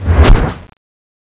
angra_zbs_skill_dash.wav